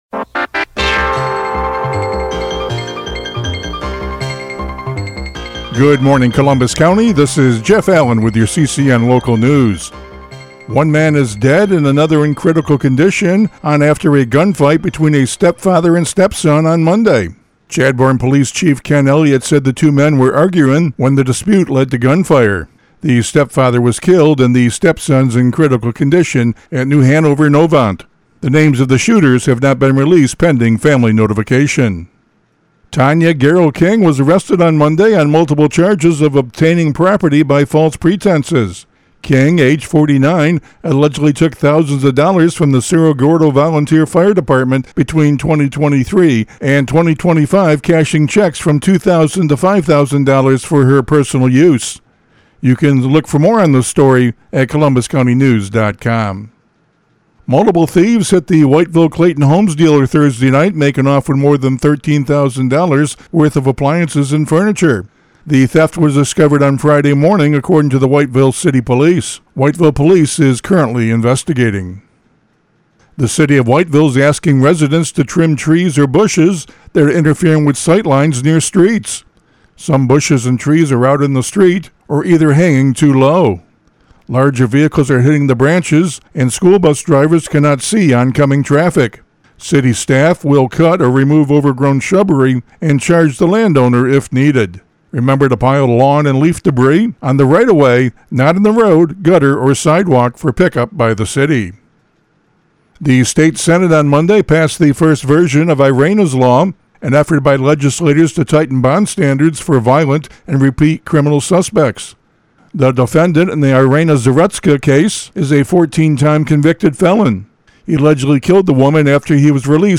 CCN Radio News — Morning Report for September 24, 2025